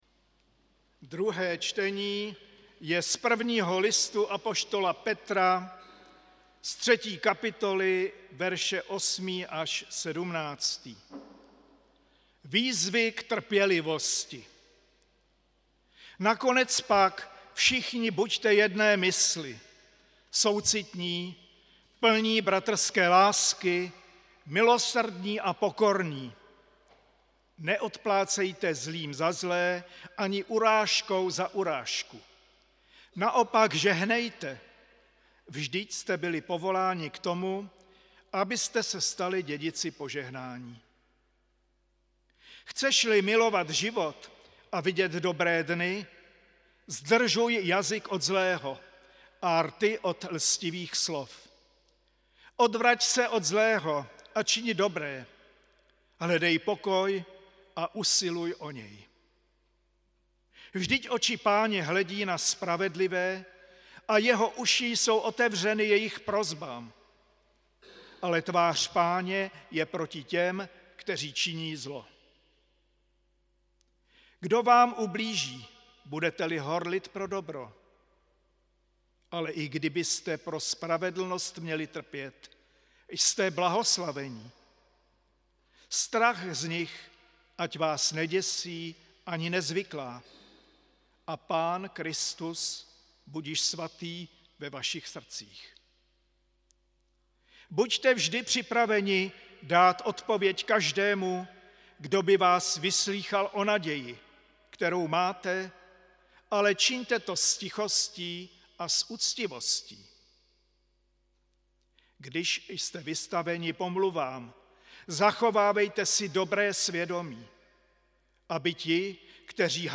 Bohoslužba se sv. VP 6. 8. 2023 • Farní sbor ČCE Plzeň - západní sbor
Bohoslužba se slavením sv. Večeře Páně.